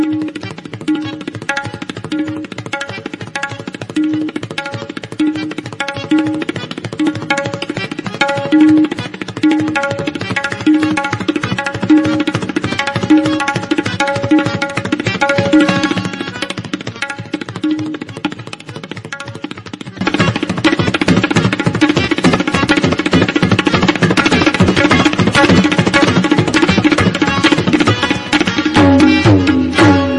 Category: Classical Ringtones